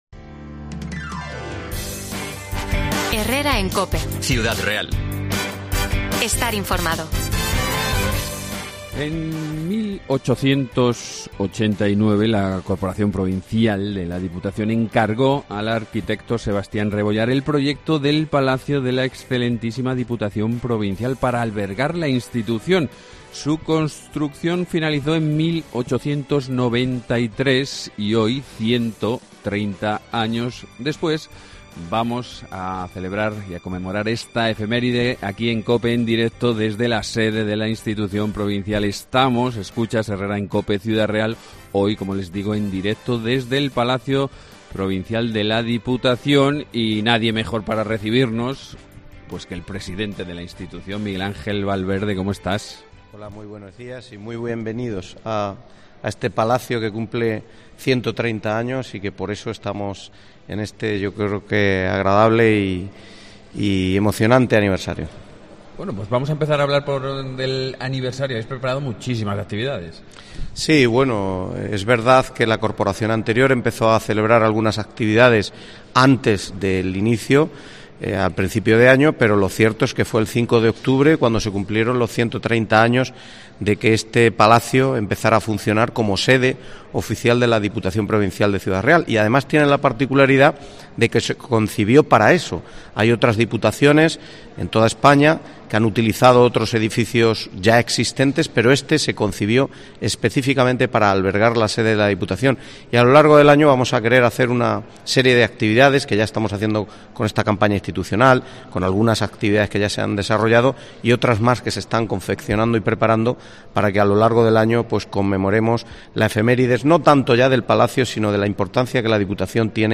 AUDIO: Herrera en COPE Ciudad Real desde el Palacio Provincial, sede de Diputación de Ciudad Real - 12,50